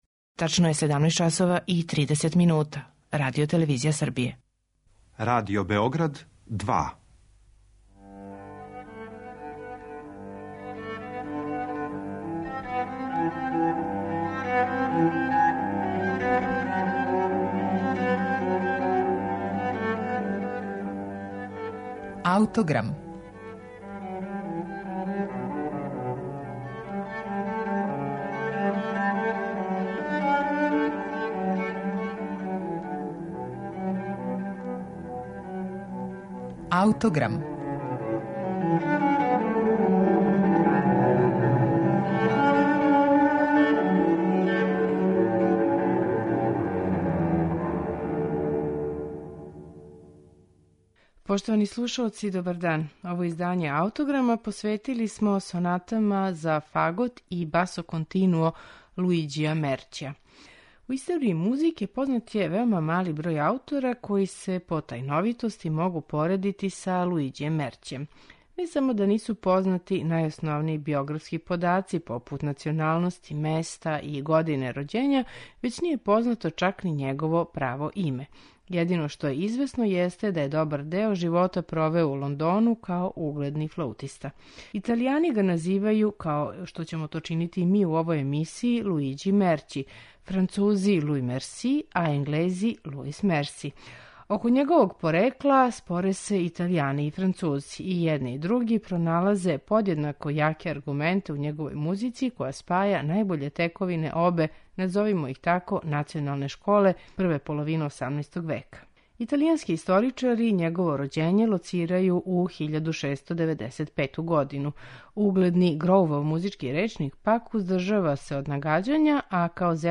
Данашњи Аутограм посветили смо једном од ретких сачуваних Мерћијевих дела - збирци Шест соната за фагот и басо континуо.
виолончело
теорба и гитара
чембало.